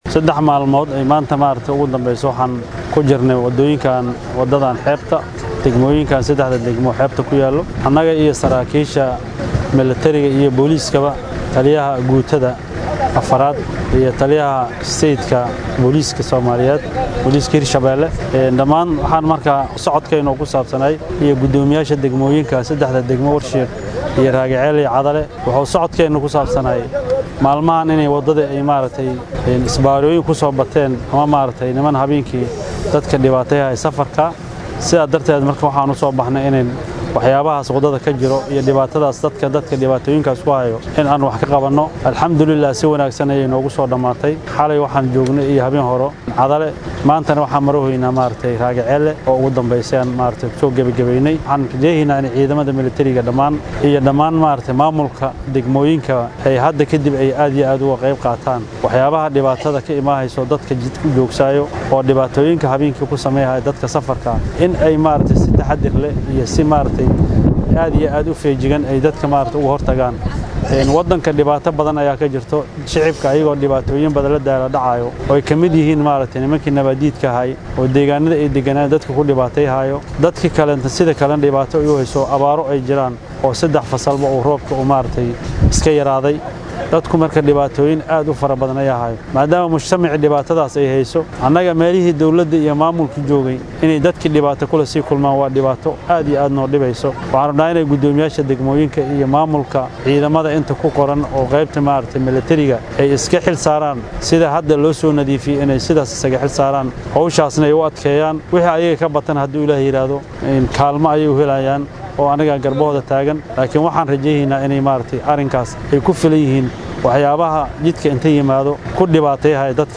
Guddoomiyaha Gobolka Shabeellaha Dhexe Axmad Meyre Makaraan (Axmad Faal) oo hoggaaminayay howlgalka oo la hadlay warbaahinta ayaa u sheegay in howlgal socday saddex maalmood lagu qaaday Isbaarooyin dhowr ah oo lagu dhibaateyn jiray shacabka iyo gaadiidleyda, islamarkaana looga qaadi jiray lacago sharci darro ah.